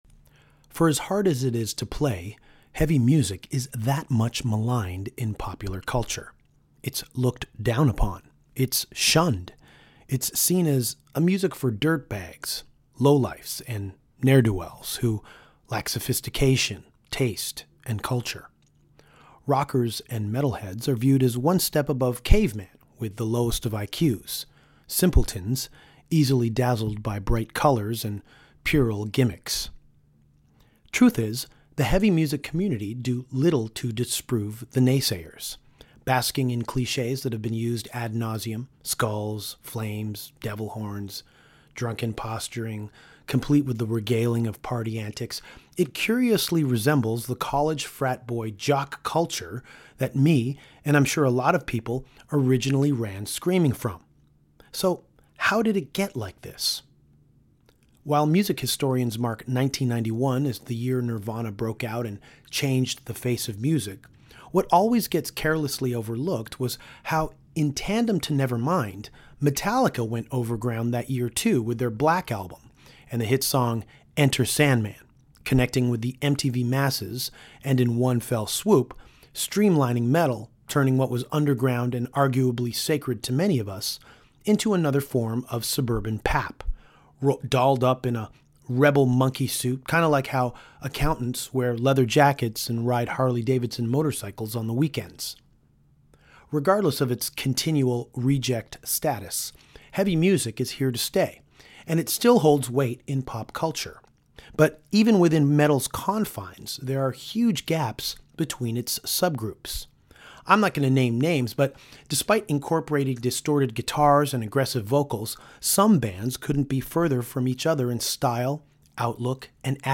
Recently in London, Danko met up with Rise Above Records head, Lee Dorian (Cathedral/Napalm Death), to talk about Church Of Misery, Gaz Jennings & Death Penalty, Lucifer, Uncle Acid & The Deadbeats, Blood Ceremony, Black Sabbath, psych bands and Admiral … Continued